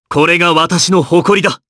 Clause-Vox_Victory_jp_b.wav